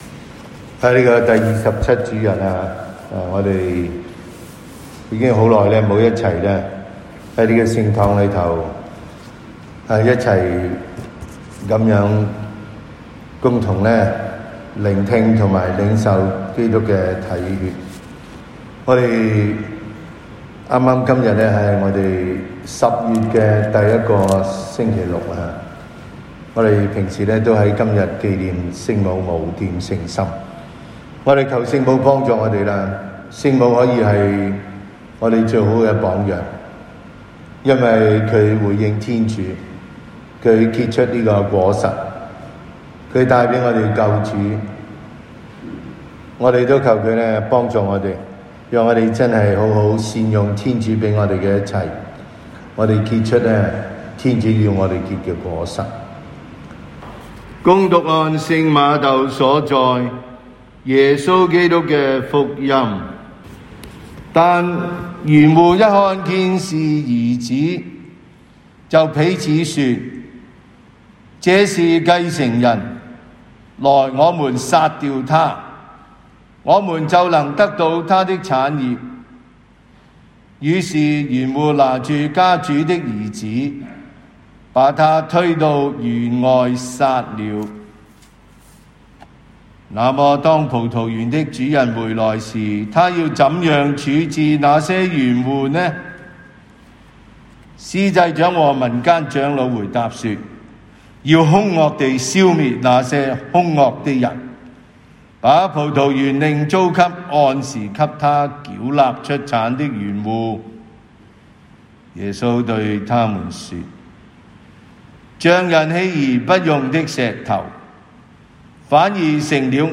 SDB 每日講道及靈修講座